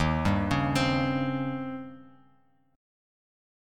Eb13 Chord
Listen to Eb13 strummed